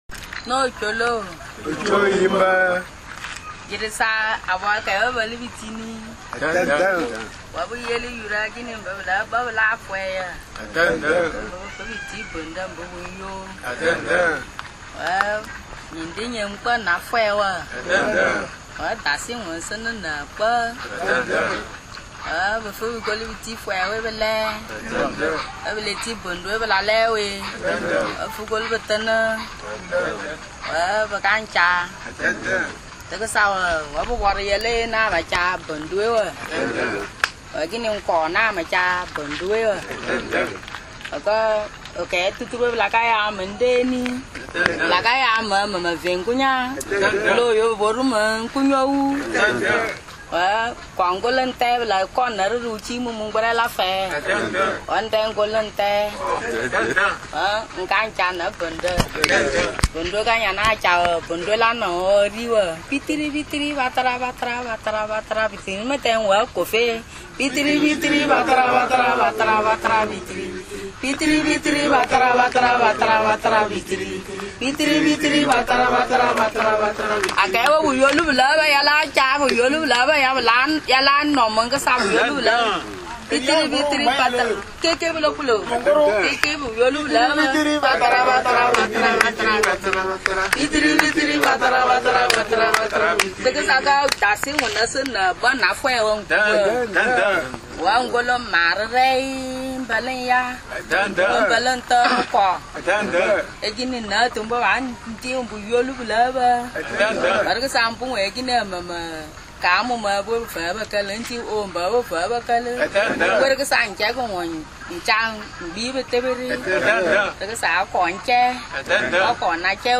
Les contes